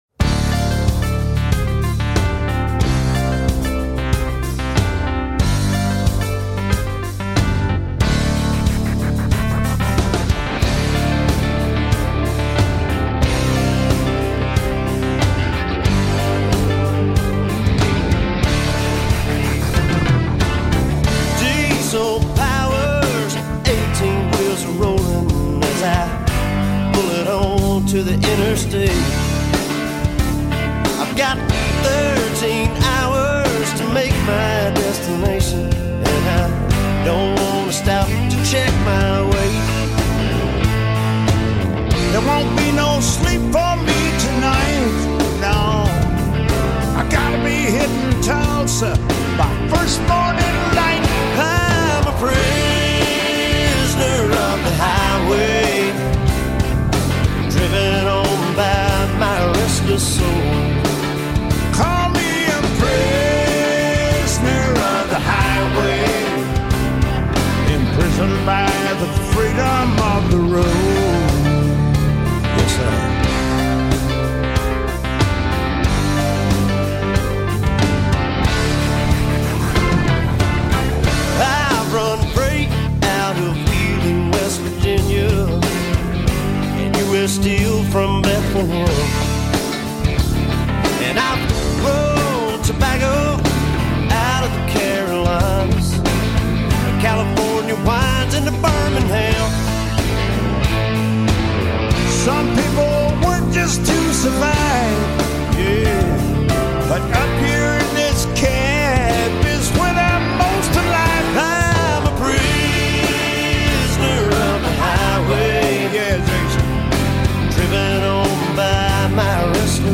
in Nashville via telephone